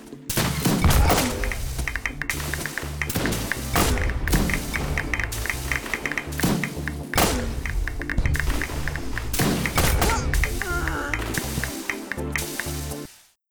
Das Klackern klingt nach den Granaten von den Türmen.
Explosionen, Klackern, Wasser in Lava.
Die Musik war verräterisch!